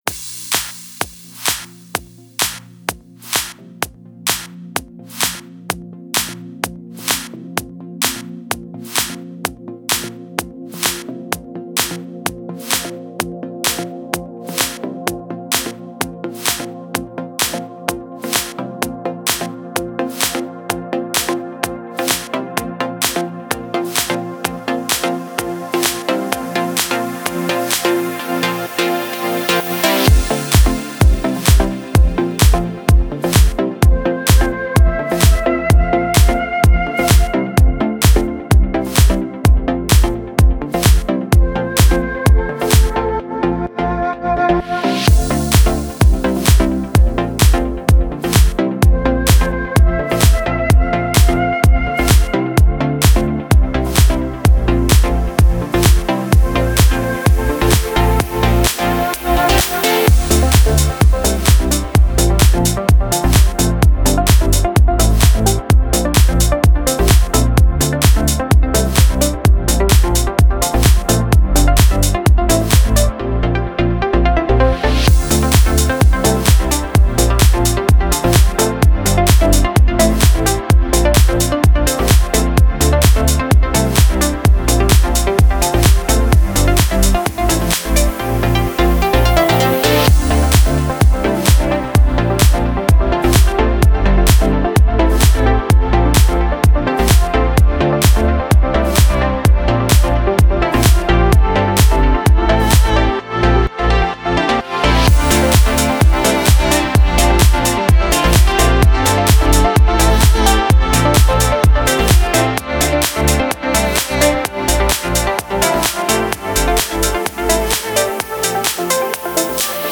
Стиль: Melodic Progressive